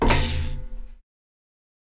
electric.ogg